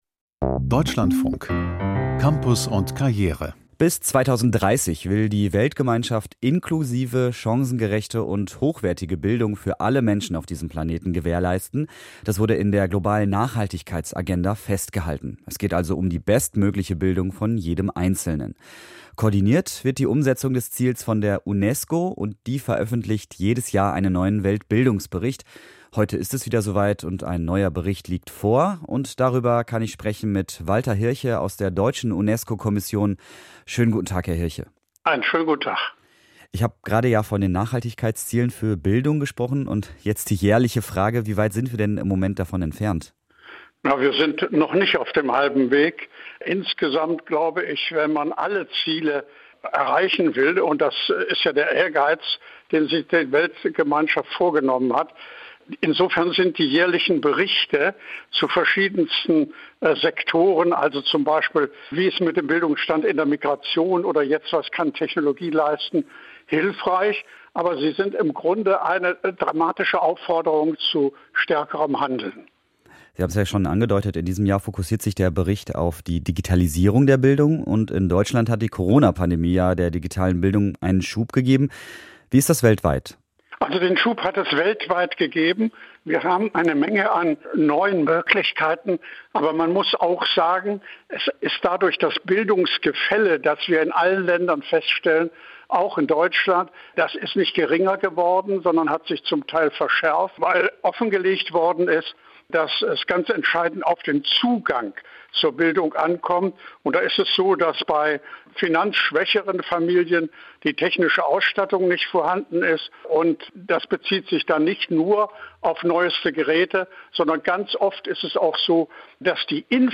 UNESCO-Weltbildungsbericht, Interview Walter Hirche